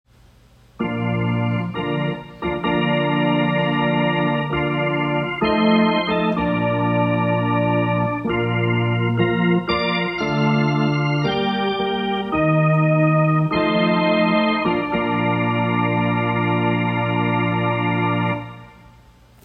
WEDDING MARCH